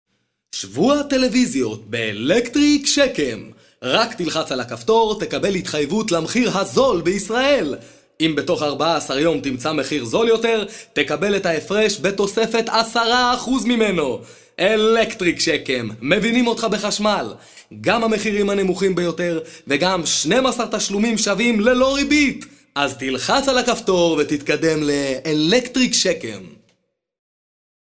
קריינות אלקטריק שקם